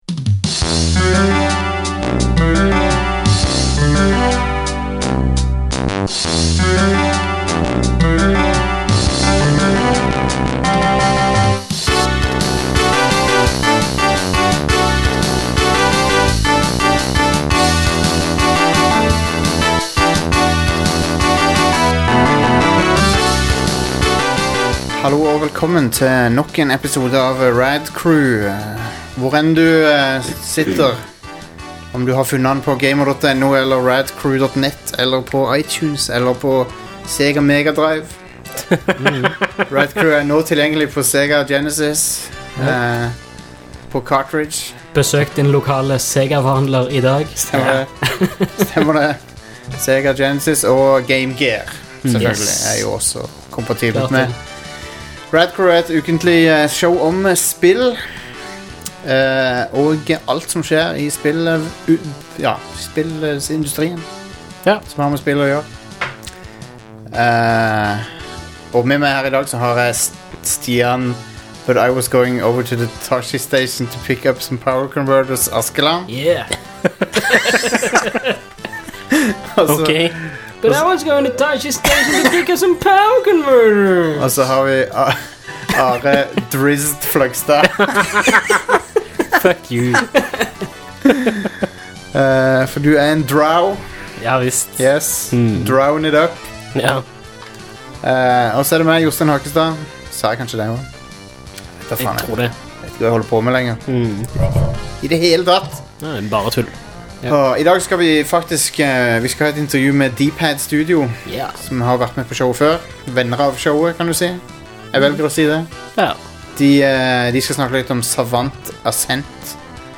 Intervju med D-Pad Studio om Savant Ascent (00:29-00:56)